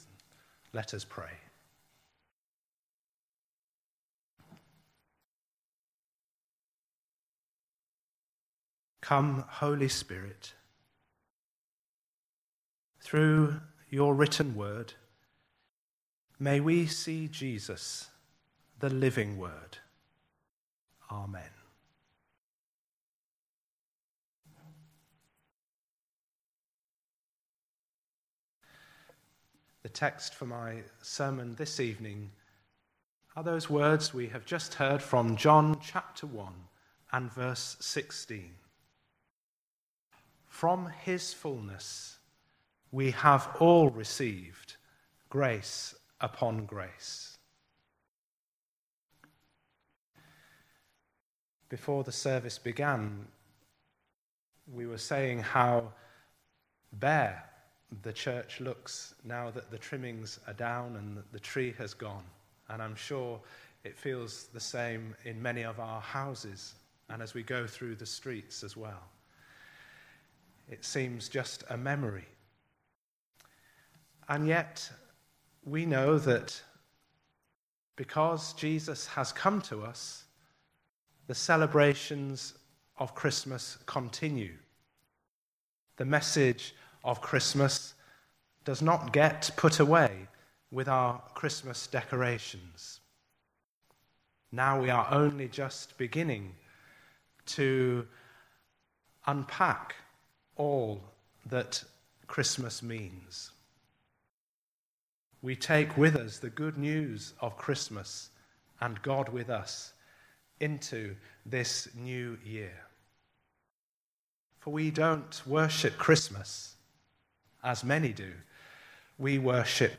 A traditional evening service.
Service Type: Sunday Evening